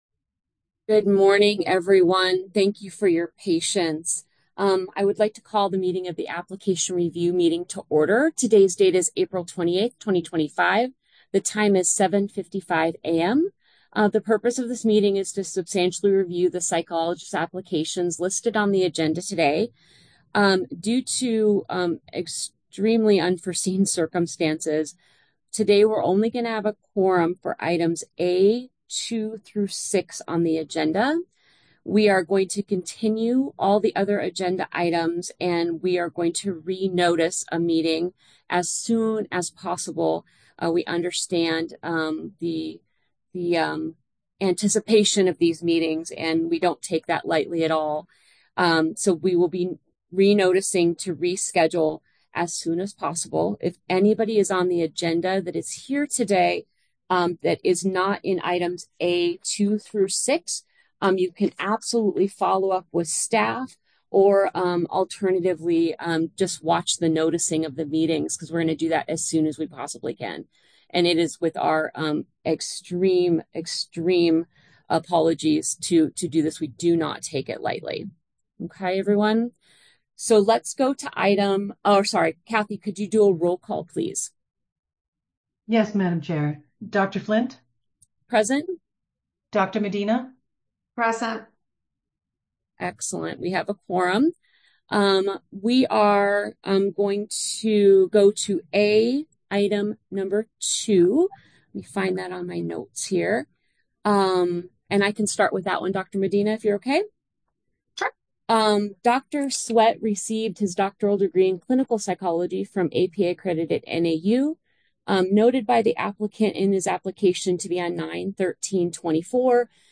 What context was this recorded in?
The meeting is being held virtually via Zoom.